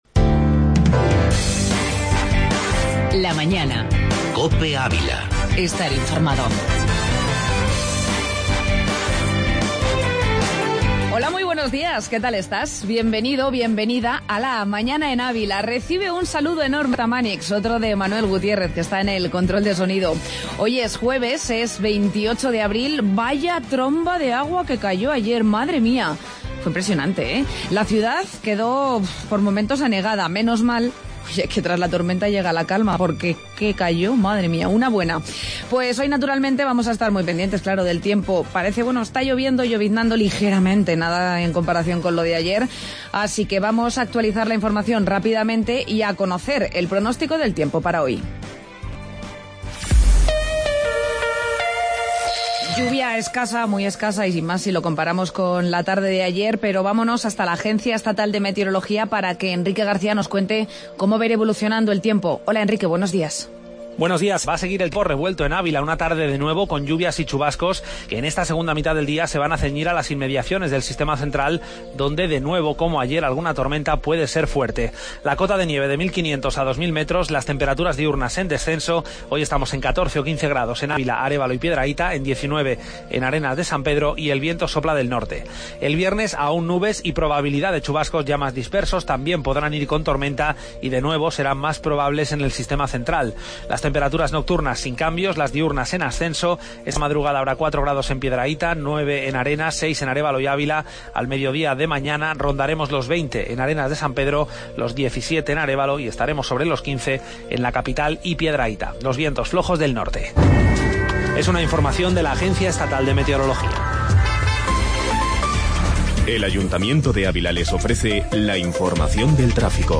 AUDIO: Buen ciudadano canino y Entrevista Alcaldesa de Santo Tomé de Zabárcos